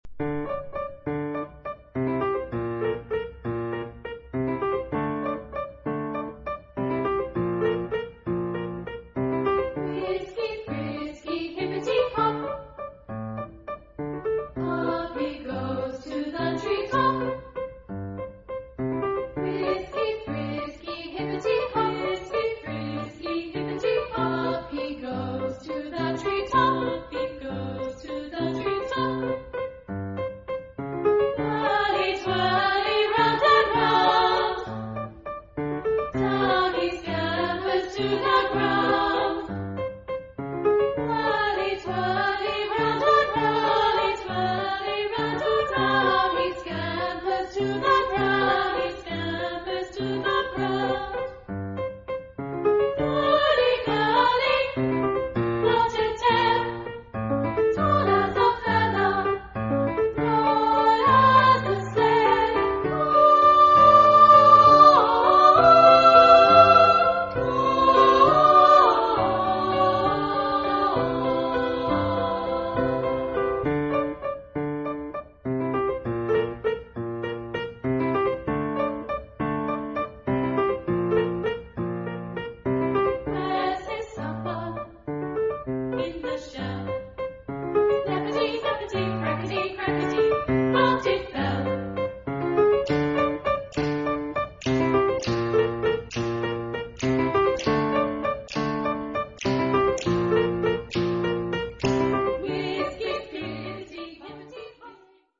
Género/Estilo/Forma: Fábula ; Profano
Carácter de la pieza : ligero
Tipo de formación coral: SSA  (3 voces iguales )
Instrumentación: Piano  (1 partes instrumentales)
Tonalidad : re mayor